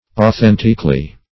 authenticly - definition of authenticly - synonyms, pronunciation, spelling from Free Dictionary Search Result for " authenticly" : The Collaborative International Dictionary of English v.0.48: Authenticly \Au*then"tic*ly\, adv.